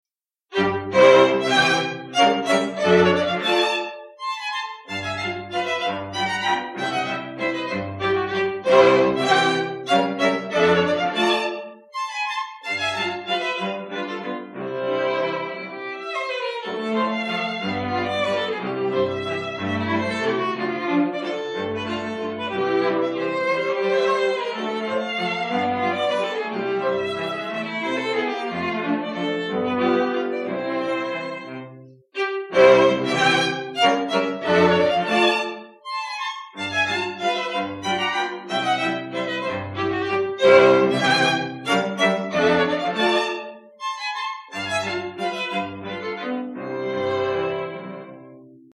Minneapolis String Quartet 1
Wedding ceremonies, receptions and parties, including outdoor ones, are only some of the types of events to which they add style.